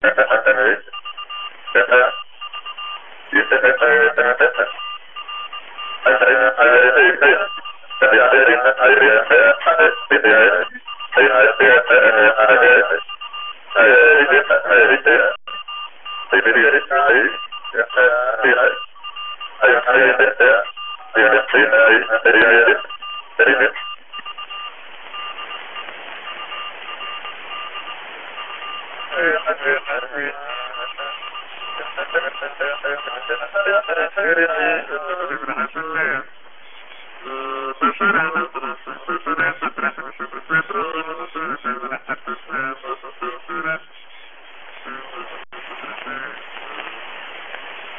短波气氛 " 短波语音气氛7
描述：录制的短波广播，对奇特的大气层声音、具体的音乐等很有帮助
标签： 环境 大气压 实验 发现-声音 噪声 短波无线电 短波-录音声艺
声道立体声